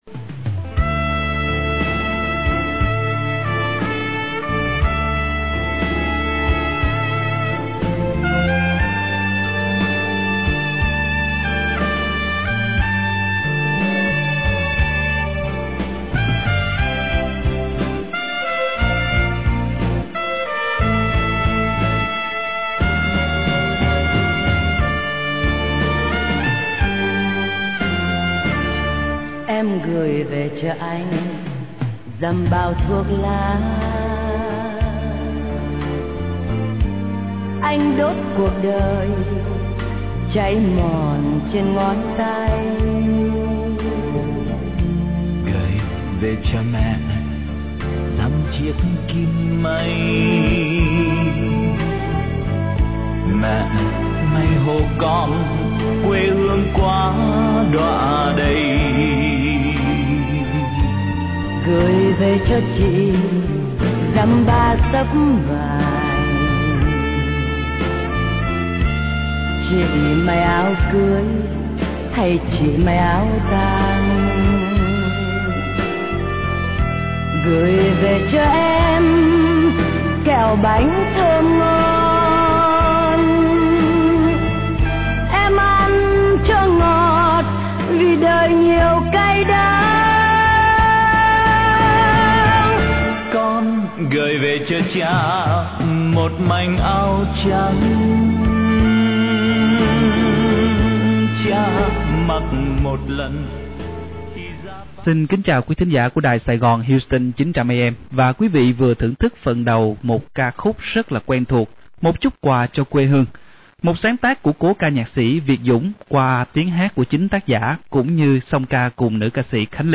Saigon Houston Radio: Hội Luận Về Giải Truyền Thông Hưng Ca – Việt Dzũng